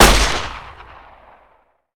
Datei:Wpn 9mm fire 2d 02.ogg